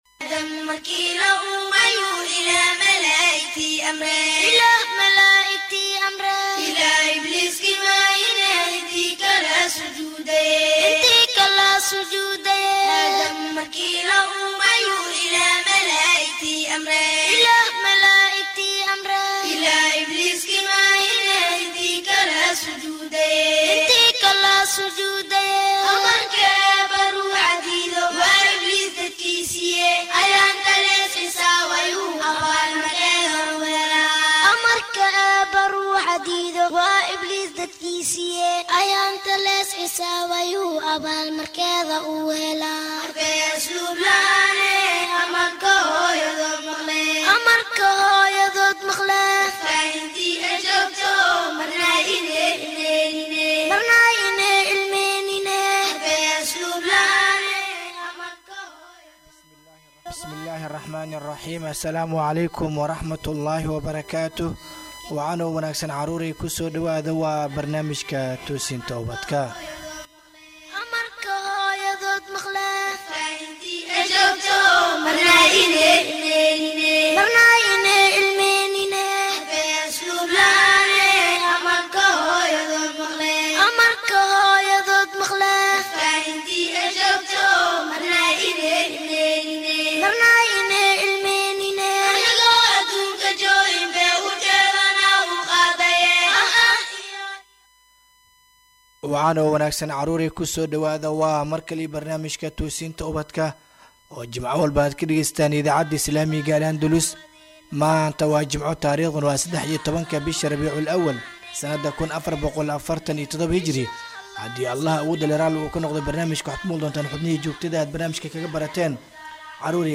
Halkan waxad ka dhagaysan kartaa barnaamijka todobaadlaha ah ee Toosinta Ubadka kaasi oo ka baxa idaacadda Andalus, barnaamijkan oo ah barnaamij ay caruurtu aad u xiisayso wuxuu ka koobanyahay dhowr xubnood oo kala ah wicitaanka iyo bandhiga caruurta, jawaabta Jimcaha, Iftiiminta qalbiga iyo xubinta su aasha toddobaadka.